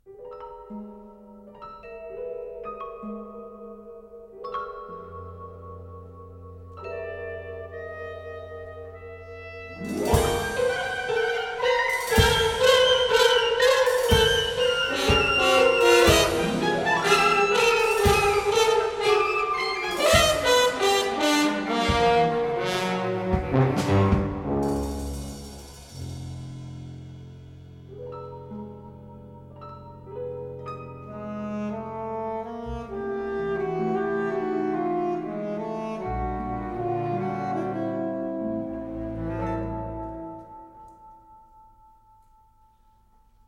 symphonic ensemble